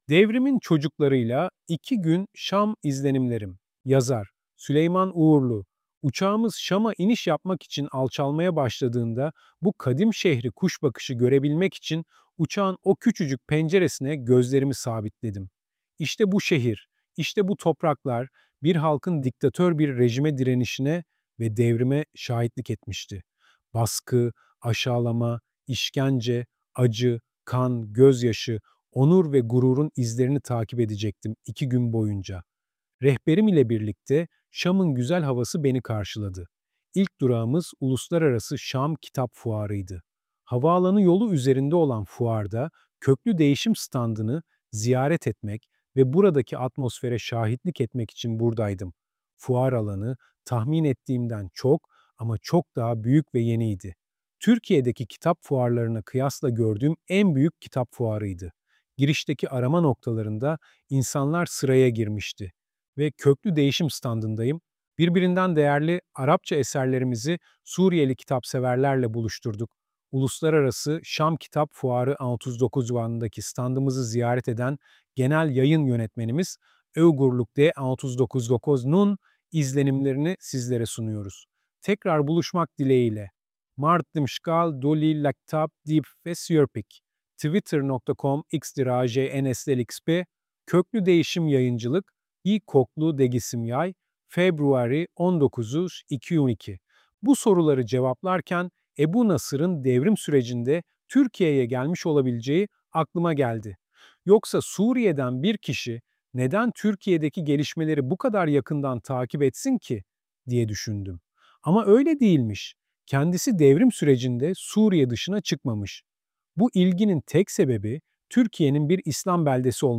Bu ses yapay zeka tarafından oluşturulmuştur